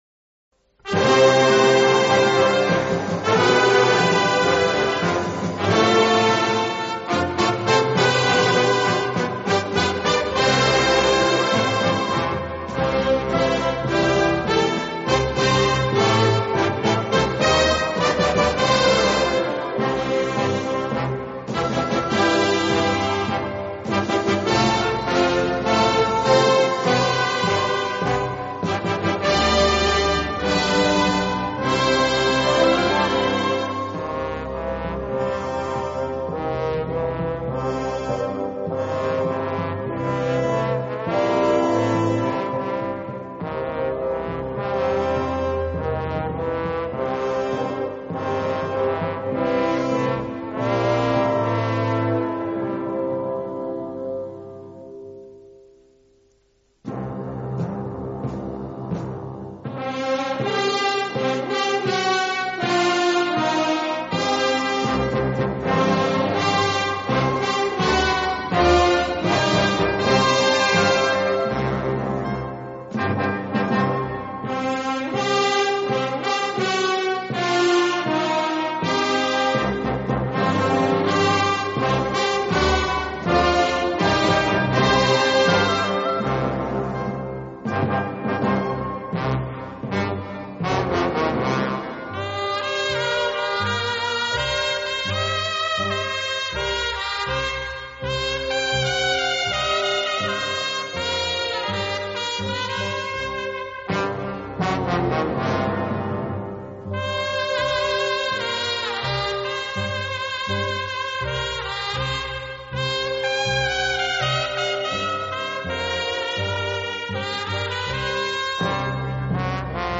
Мусиқа ва тарона Эрон мусиқаси